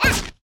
Sfx Player Kick Sound Effect
sfx-player-kick-2.mp3